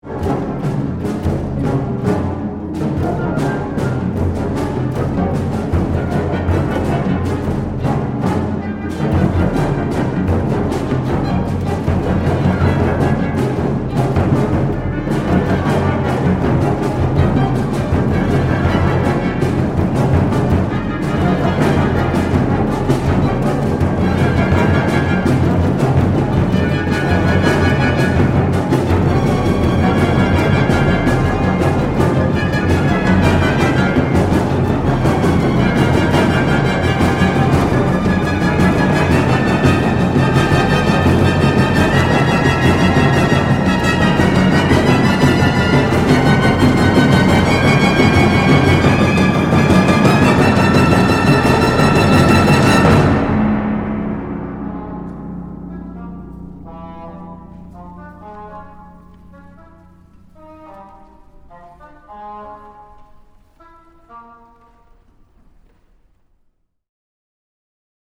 Audio excerpts from the world premiere